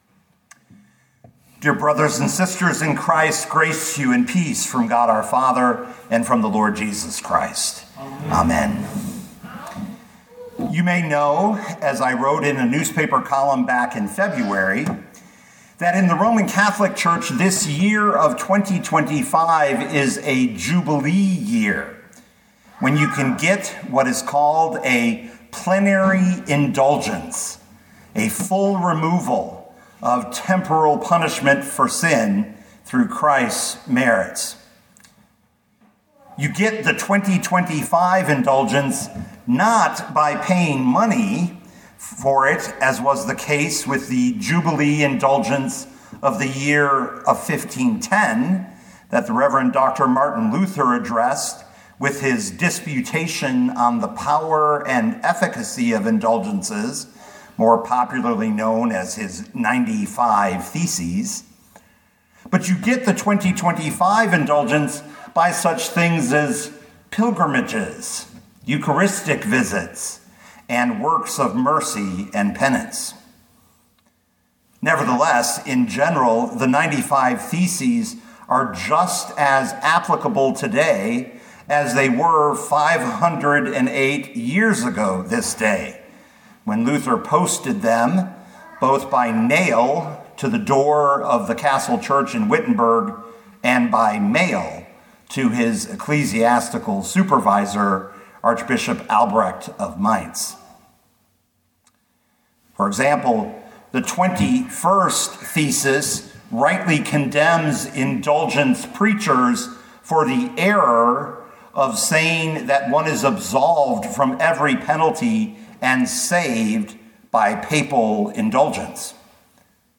2025 John 1:16 Listen to the sermon with the player below, or, download the audio.